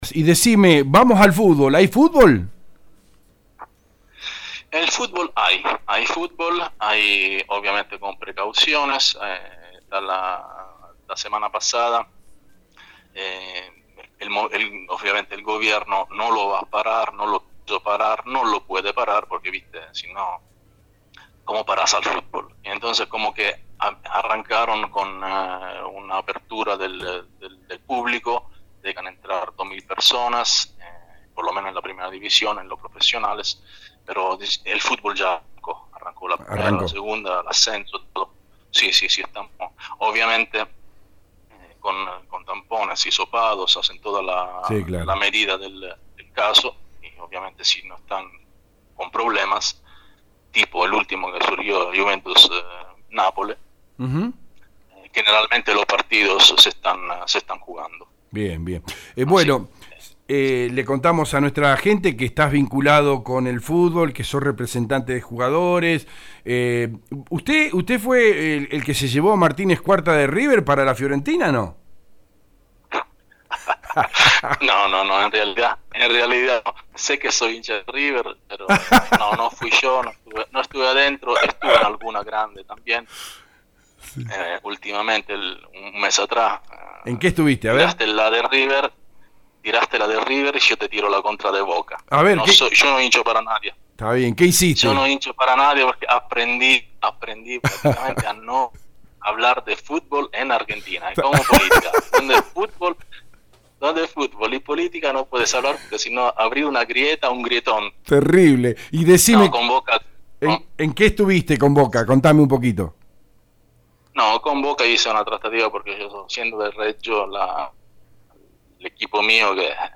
(Nota radial del 8/10/20)